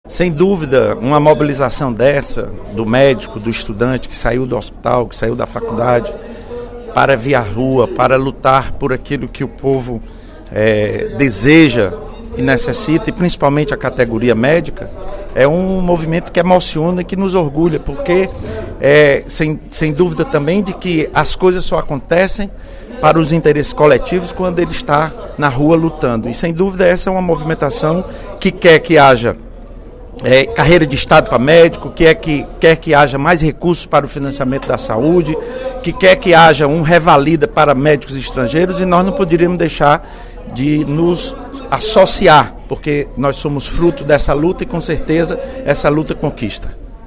O deputado Lula Morais (PCdoB) ressaltou, nesta quarta-feira (03/07), durante o primeiro expediente da sessão plenária, a luta da categoria médica pela melhoria na saúde do Brasil.